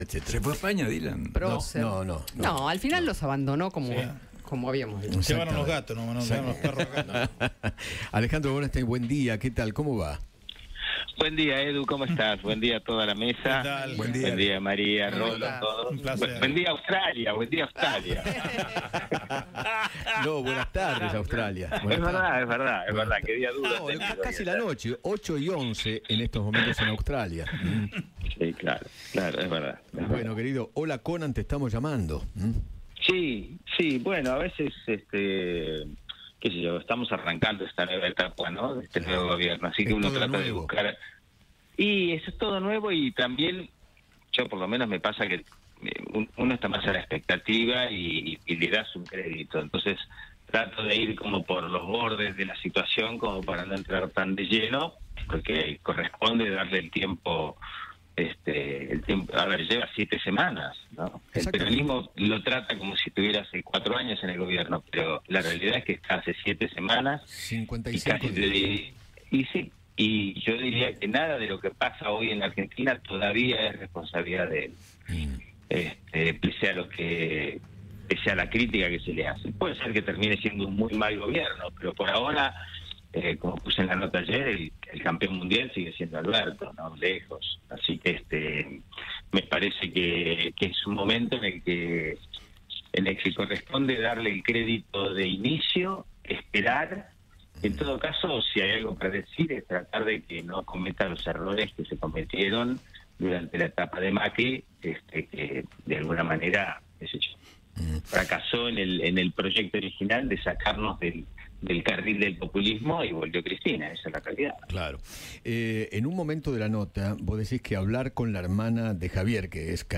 Alejandro Borensztein conversó con Eduardo Feinmann sobre la presidencia de Javier Milei, las críticas del peronismo y analizó las primeras semanas del Gobierno libertario.